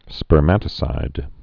(spər-mătə-sīd, spûrmə-tə-)